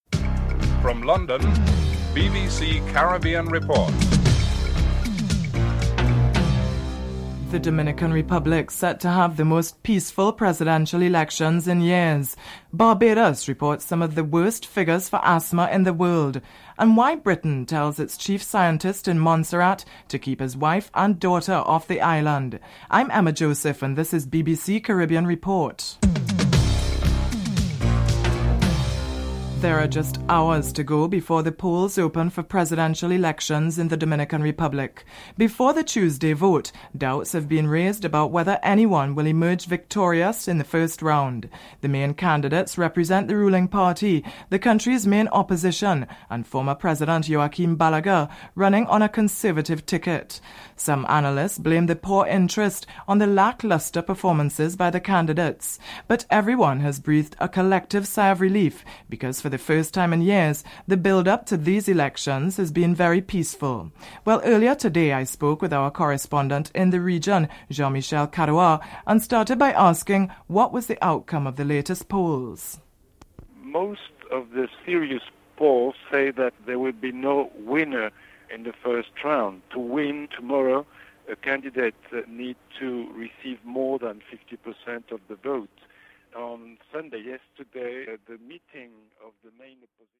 1. Headlines: (00:00 – 00:28)